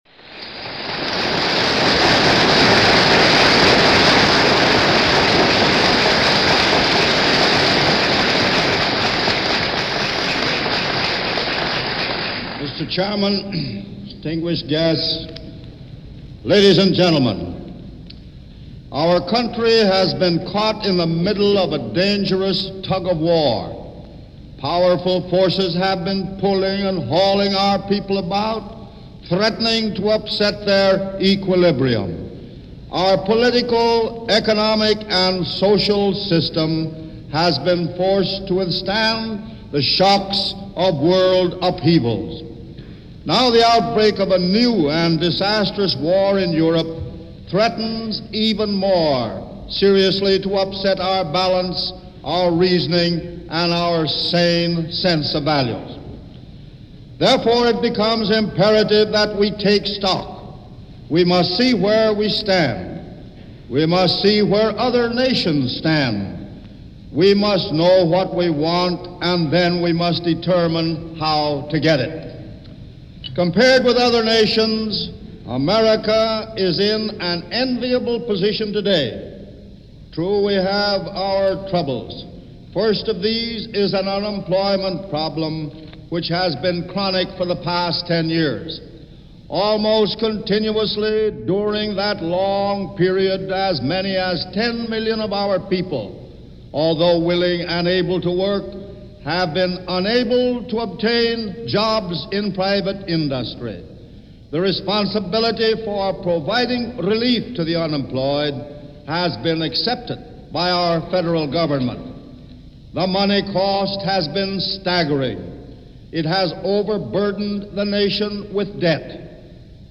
William Green was President of the American Federal of Labor, before the merger with Congress Of Industrial Organizations, addressing a gathering on the subject “Challenges To Democracy”.